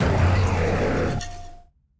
Cri de Rugit-Lune dans Pokémon Écarlate et Violet.